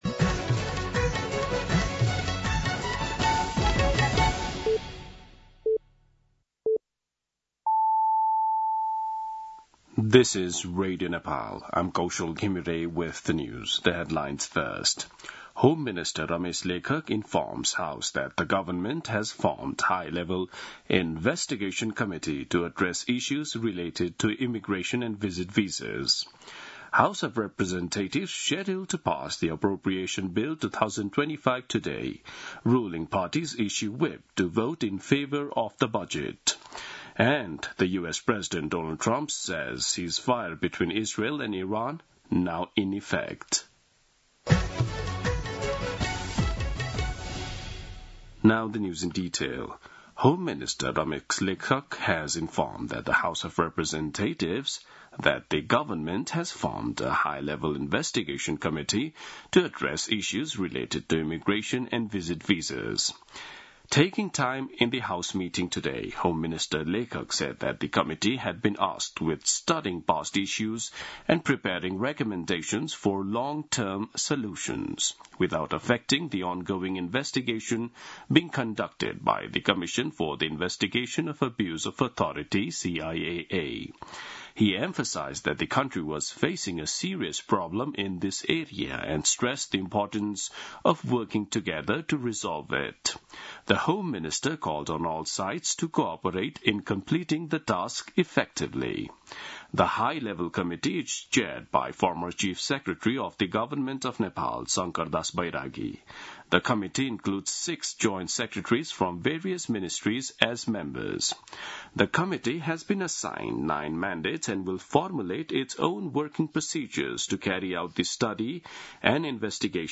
दिउँसो २ बजेको अङ्ग्रेजी समाचार : १० असार , २०८२
2-pm-English-News-10.mp3